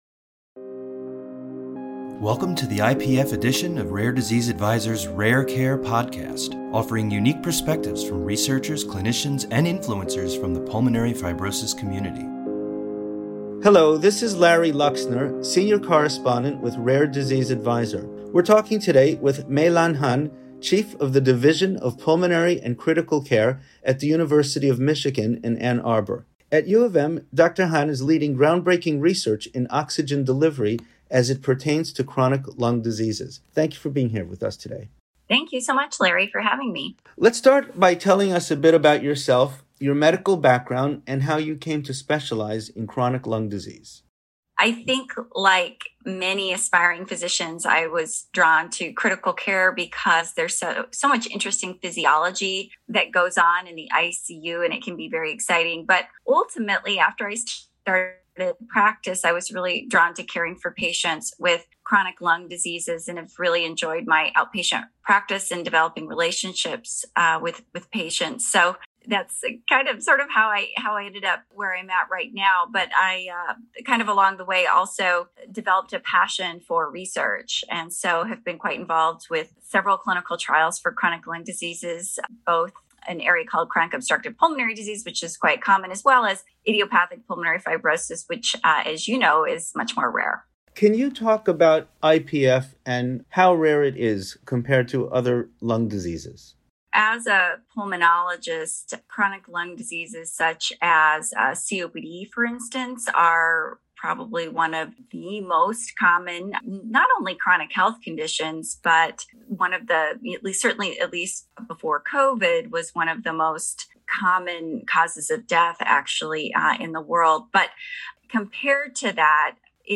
Rare Care Podcast / An Interview